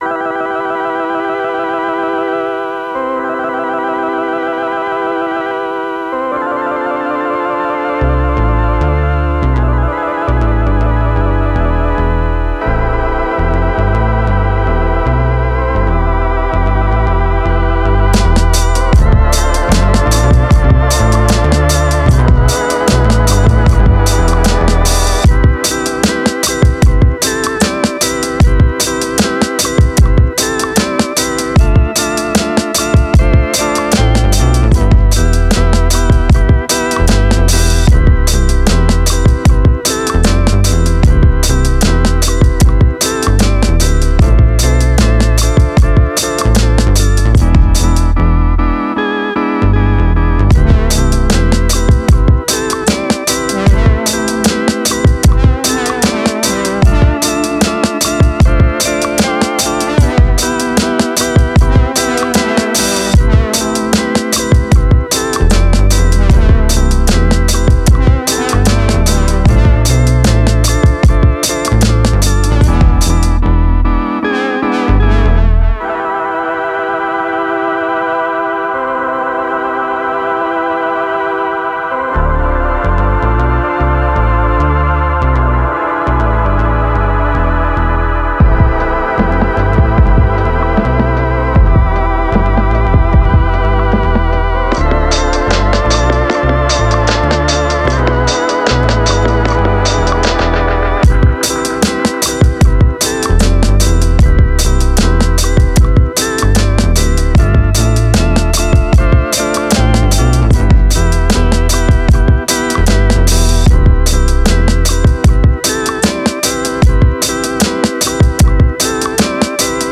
Laidback beats carve out a quirky groove.